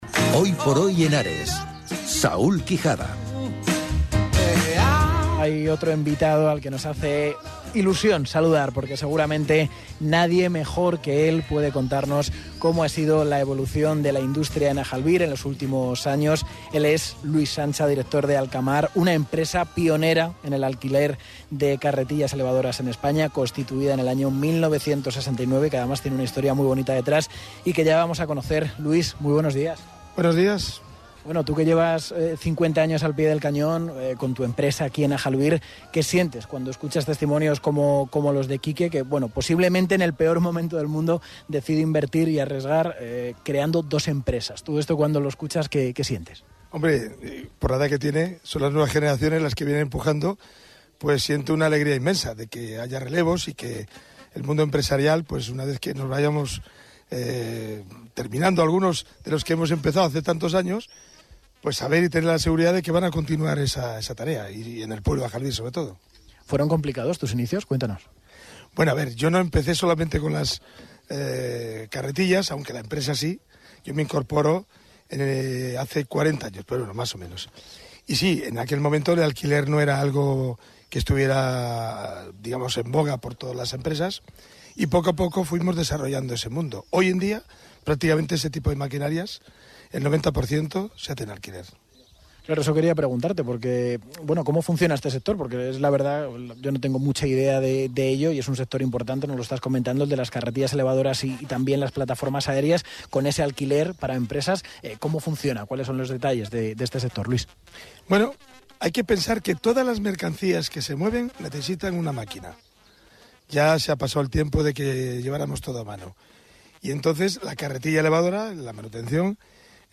El estudio móvil estaba montado al aire libre, en las cercanías de la plaza Mayor, junto a la Iglesia de la Purísima Concepción.